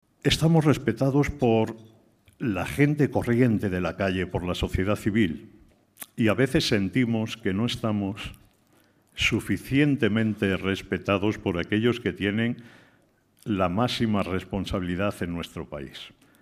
En una conferencia organizada por Fórum Europa pasa revista a la actualidad social y reclama "respeto y reconocimiento" para las organizaciones de la sociedad civil